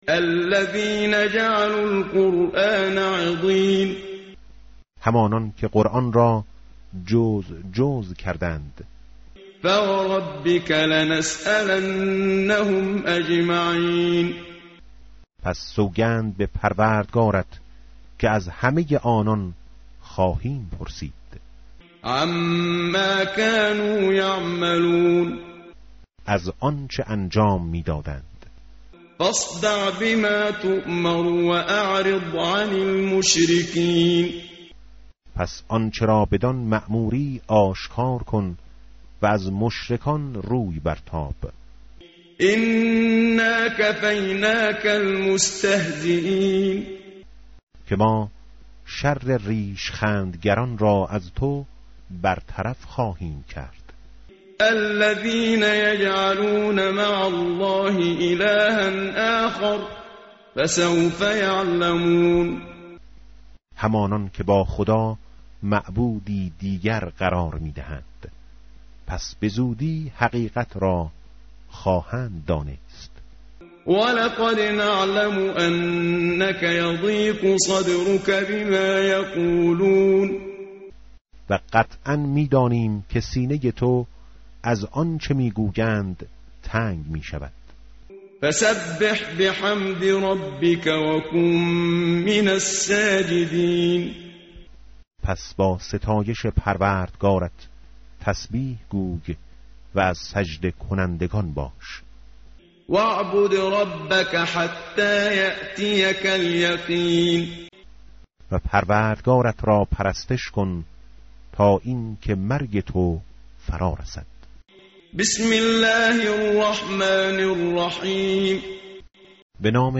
متن قرآن همراه باتلاوت قرآن و ترجمه
tartil_menshavi va tarjome_Page_267.mp3